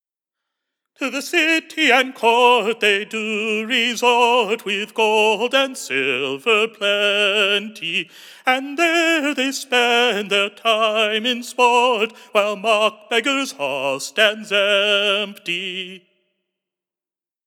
Stanza 2, lines 5–8, of first “Mock-Beggar Hall” ballad; sung to “Northern Nancy” (minor)